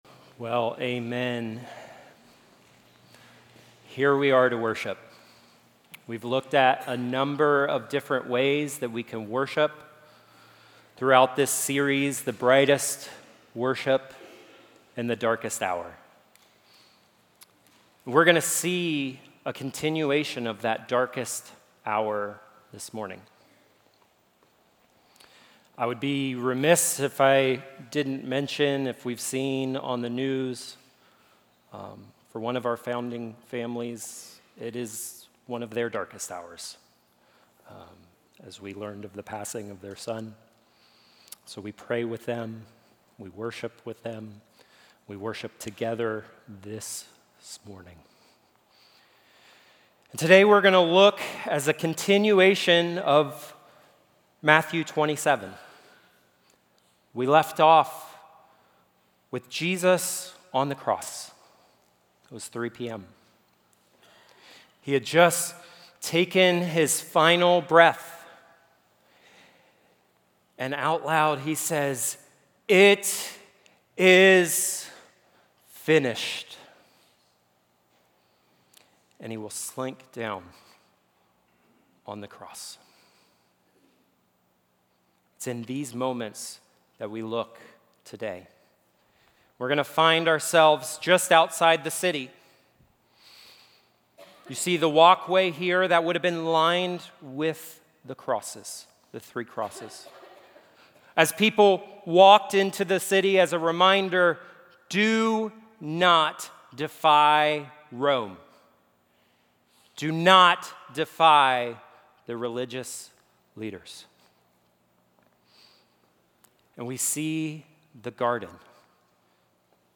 Equipping Service / Matthew: The Brightest Worship In The Darkest Hour / Worship Through Giving